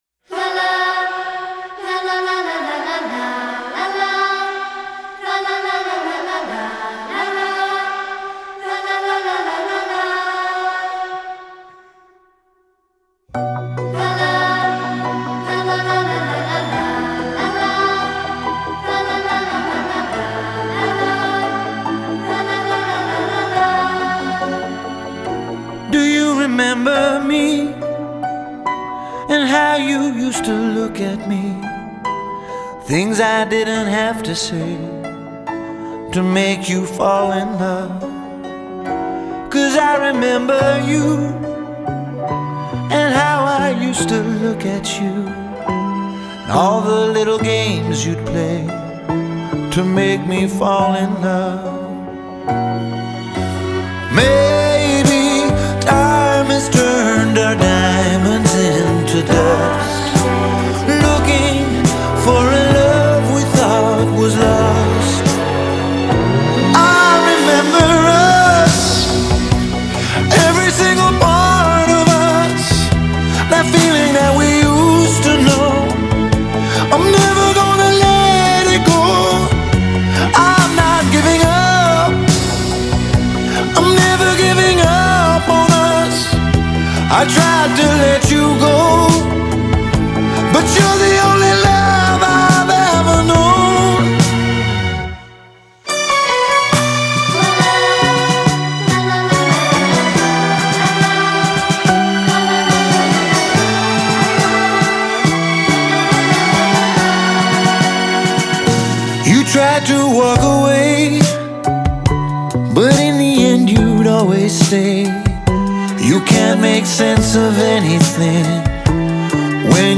Genre : Singer & Songwriter